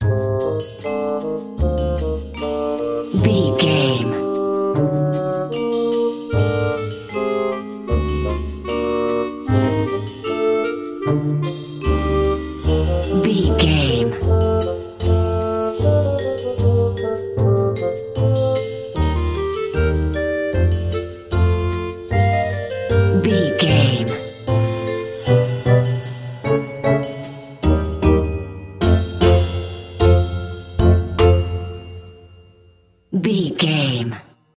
Ionian/Major
F#
Slow
orchestra
strings
flute
drums
circus
goofy
comical
cheerful
perky
warm
Light hearted
quirky